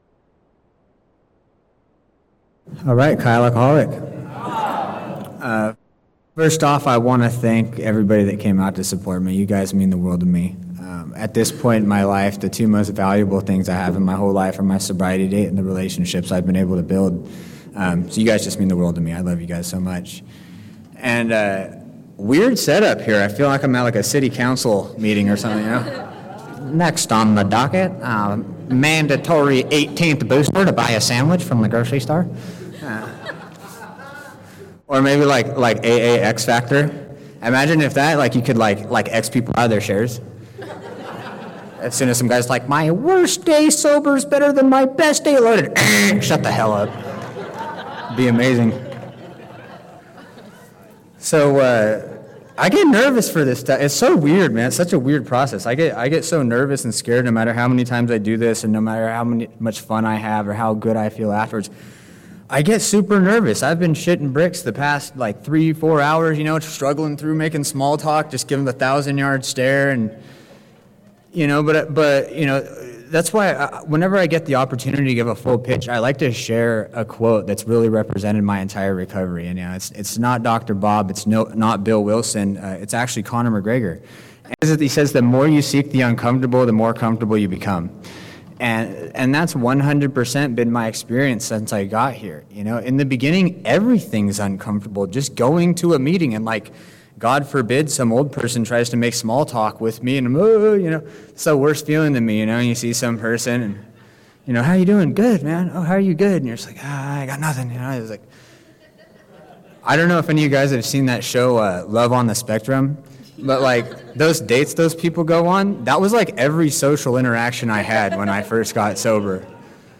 36th Annual Serenity By The Sea